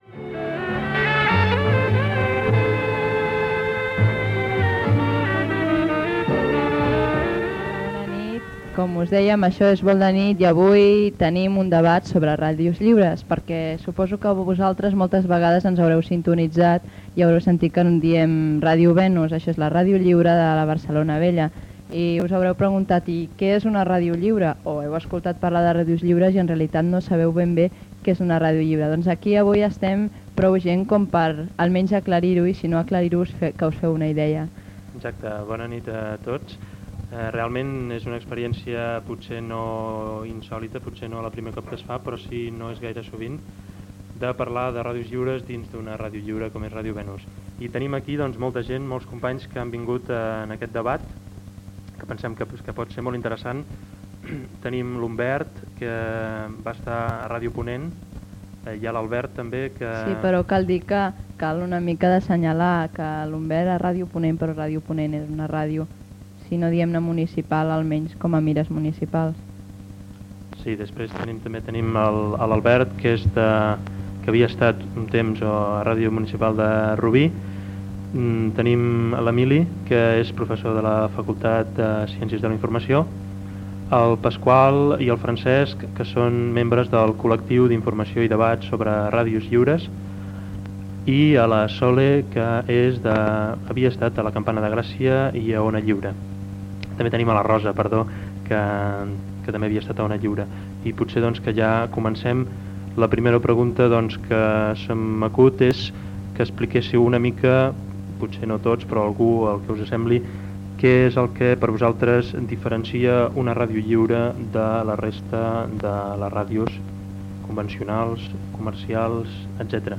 Debat sobre ràdios lliures